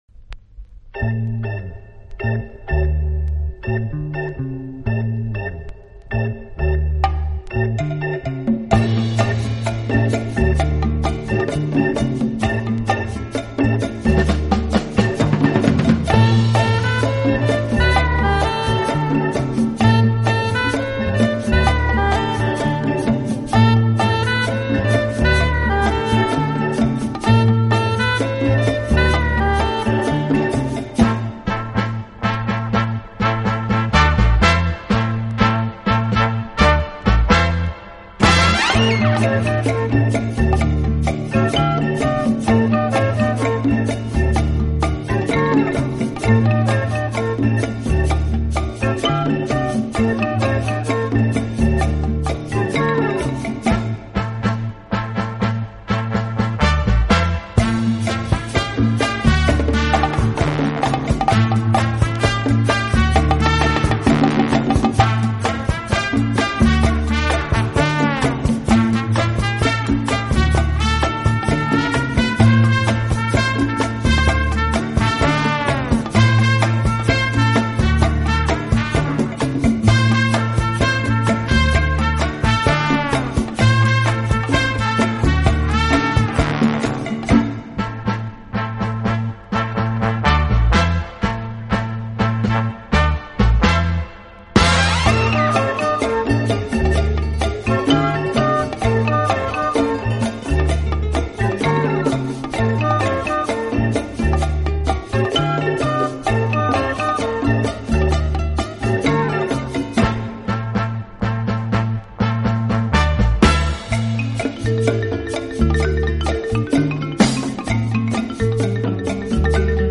【轻音乐】
以擅长演奏拉丁美洲音乐而著称。
乐器的演奏，具有拉美音乐独特的韵味。